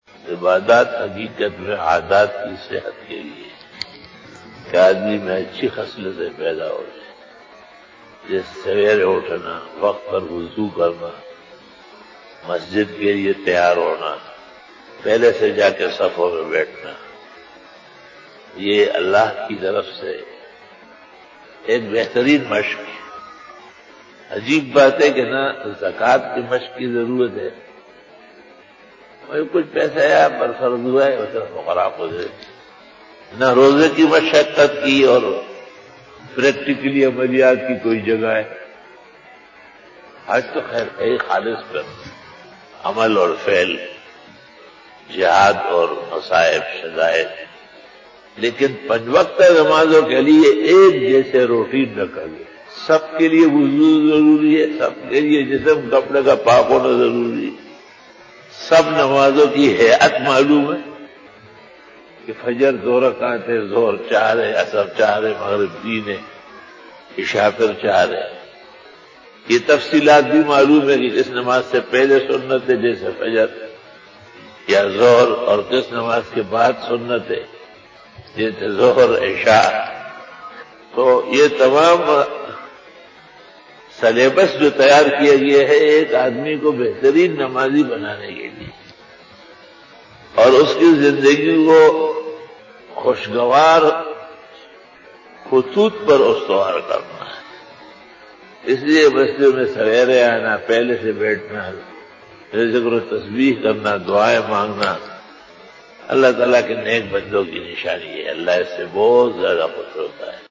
Fajar bayan 26 September 2020 ( 08 Safar ul Muzaffar 1442HJ) Saturday
بعد نماز فجر بیان 26 ستمبر 2020ء بمطابق 08 صفر 1442ھ بروزہفتہ